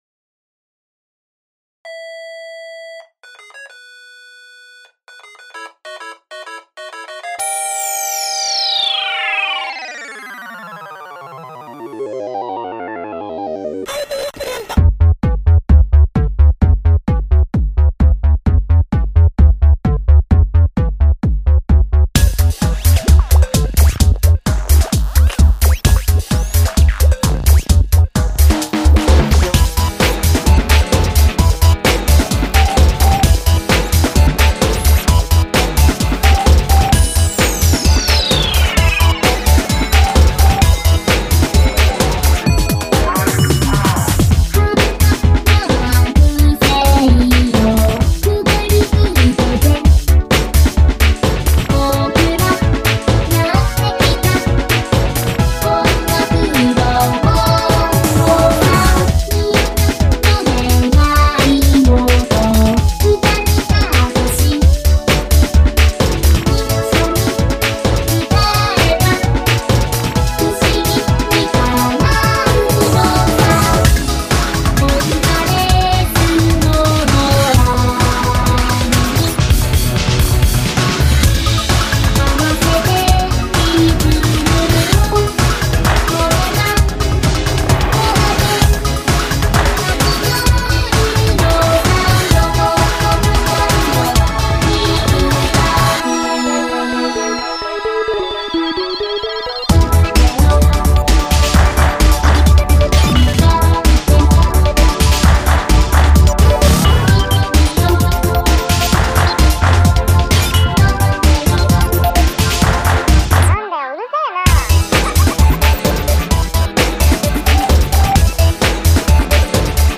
原曲アレンジとなってしまいました
MXR 185 Drum Computer
DECtalk